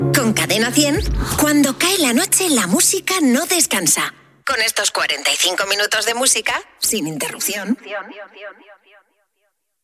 Indicatiu de nit dels 45 minuts de música sense interrupció.